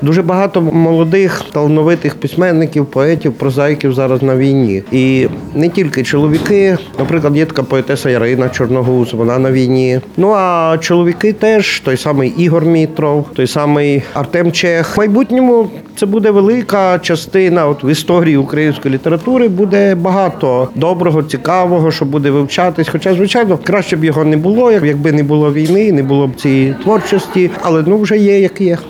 – Ukraińska wojenna literatura jest bardzo różnorodna – mówi jeden z najwybitniejszych współczesnych ukraińskich pisarzy, Ołeksandr Irwanec. W rozmowie z Radiem Lublin, pisarz wskazuje, że po wybuchu pełnoskalowej agresji, w Ukrainie pojawiło się wiele nowych autorów, a ich twórczość przedstawia realia wojny oraz ludzi żyjących w tym okresie historii.